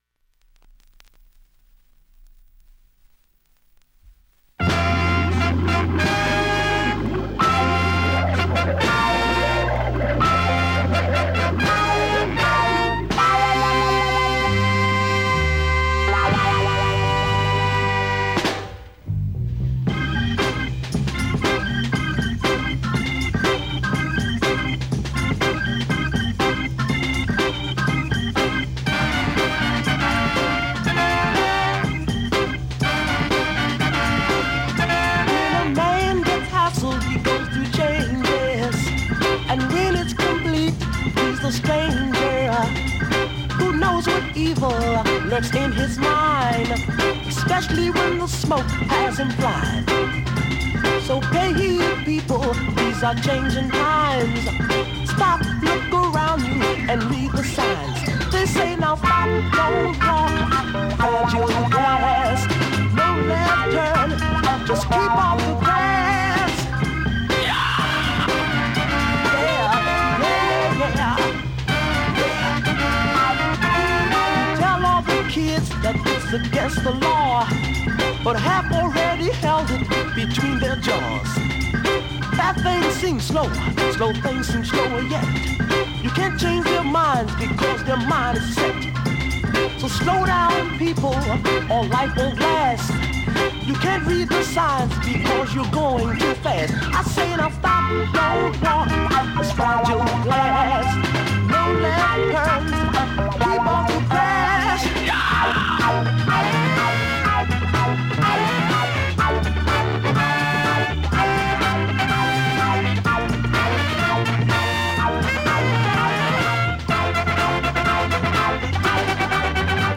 現物の試聴（両面すべて録音時間６分３秒）できます。